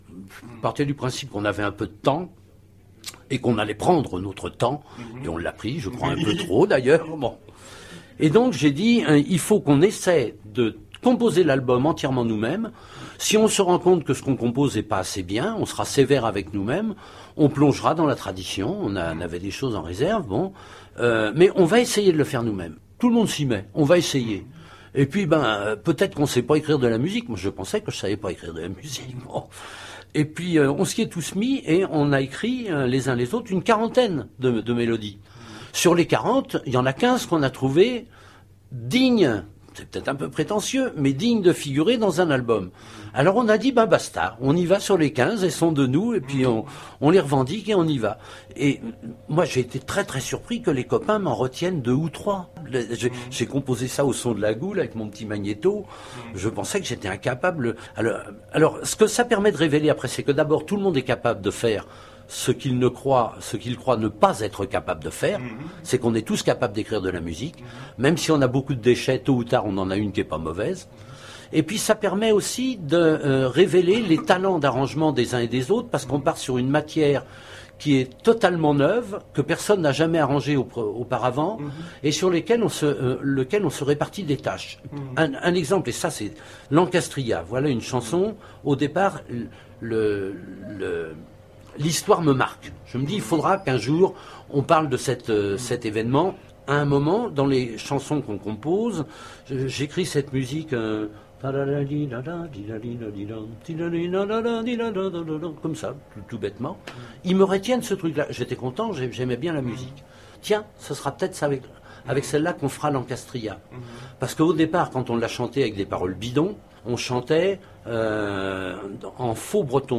Abysses,l'INTERVIEW